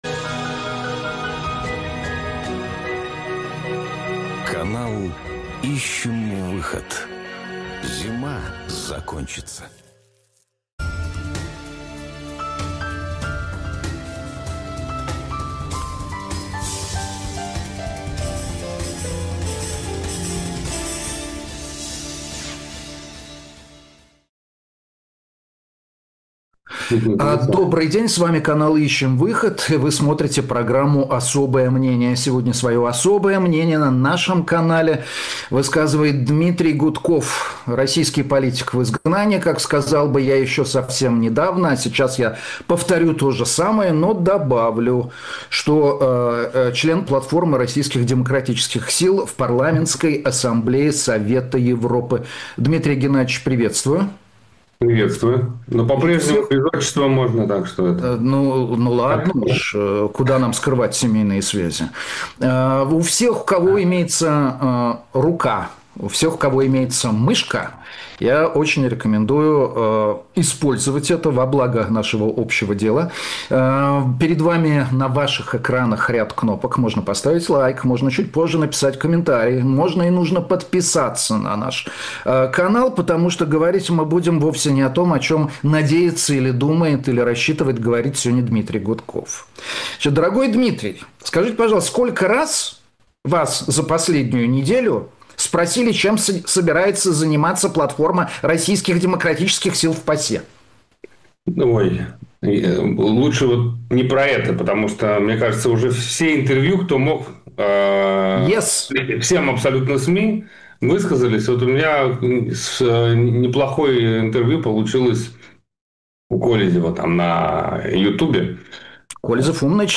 Эфир ведёт Дмитрий Губин